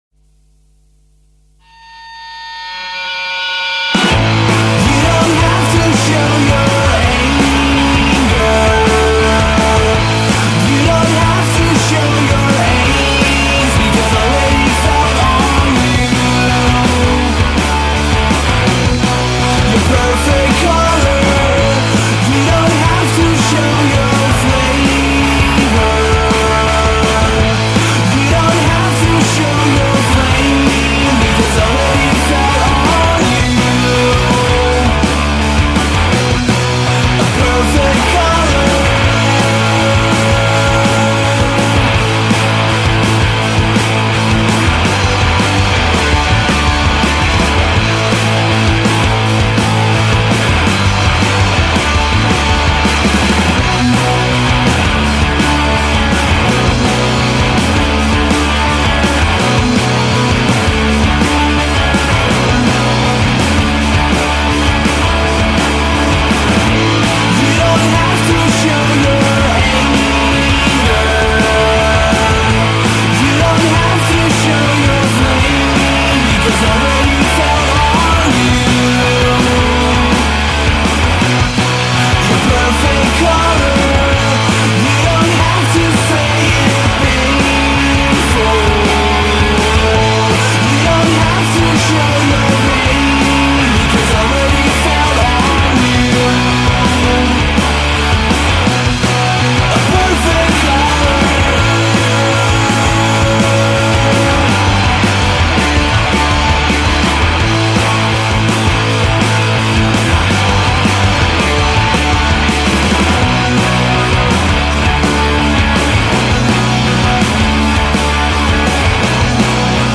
indie rock band